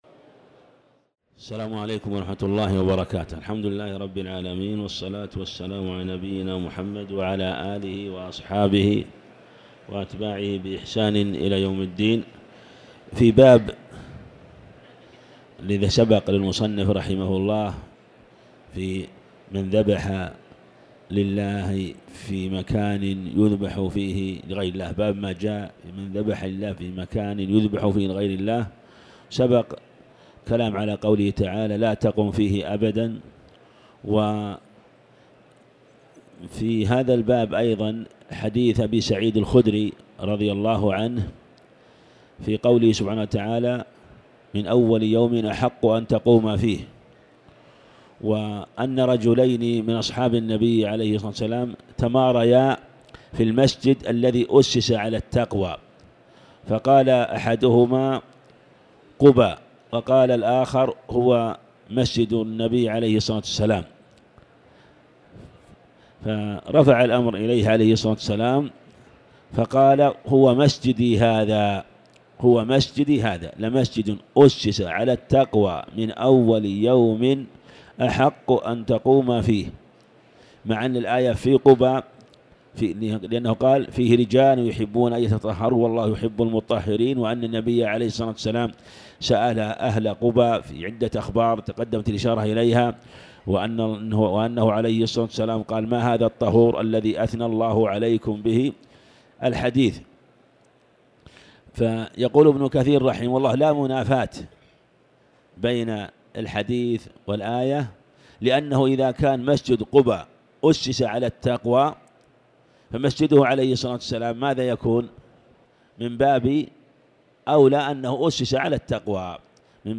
تاريخ النشر ١٤ رمضان ١٤٤٠ هـ المكان: المسجد الحرام الشيخ